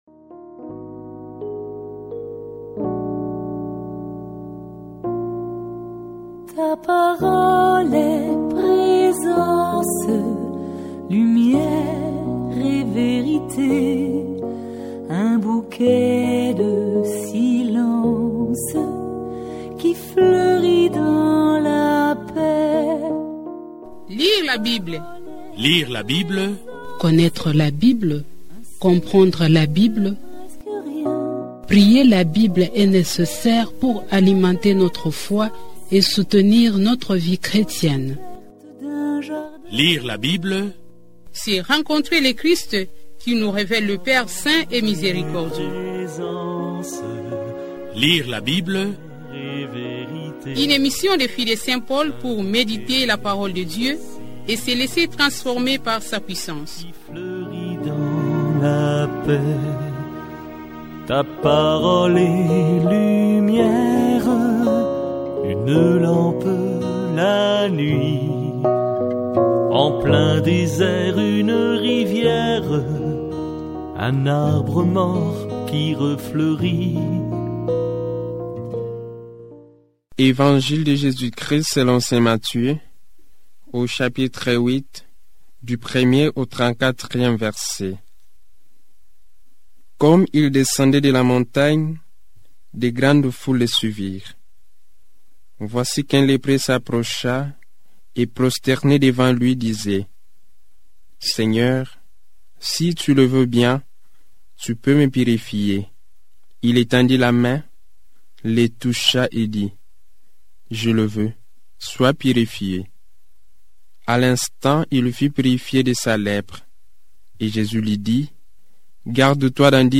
Una delle iniziative di spicco dell’Anno Biblico di Famiglia Paolina è la proposta di lettura continua della Bibbia in lingua francese.
Resa più fruibile grazie alla valorizzazione di musiche e brevi commenti, l’iniziativa apre il testo sacro a tutti, favorendo chi viaggia, chi ha difficoltà di lettura, chi è ammalato o chi semplicemente desidera lasciarsi accompagnare da quella Parola che sempre illumina gli occhi e scalda il cuore.